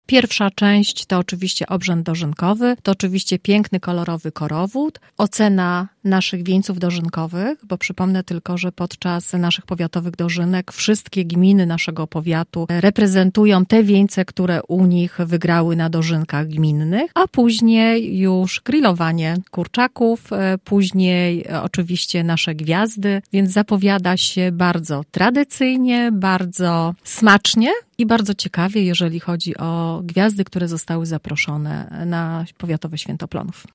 Mówi starosta gorzowski Małgorzata Domagała.